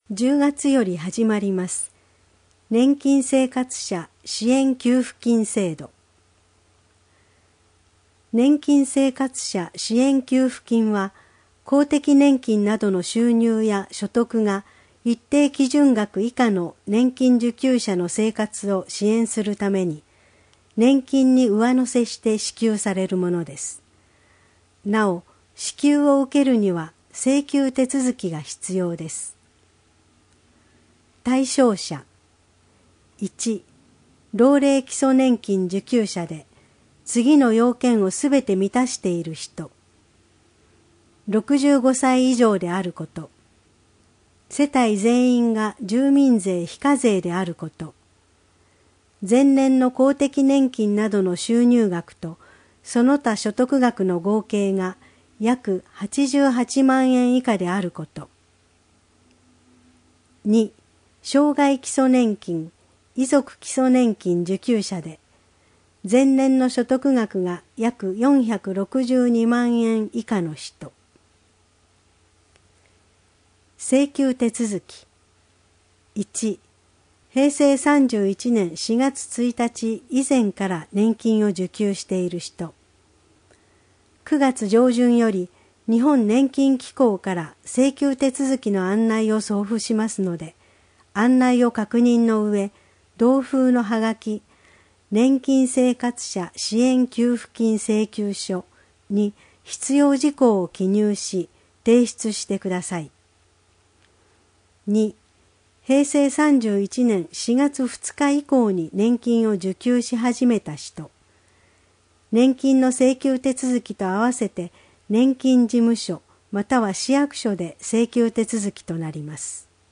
本市の依頼により富田林市朗読ボランティアグループ「くさぶえ」が視覚に障がいをお持ちの人などのために製作し、貸し出しているテープから抜粋して放送しています。